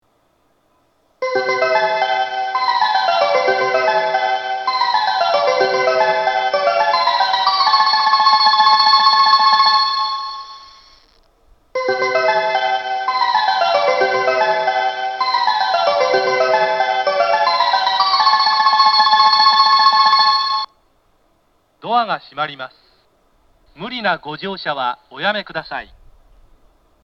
発車メロディー
1.9コーラスです!停車時間のある列車が鳴りやすいです。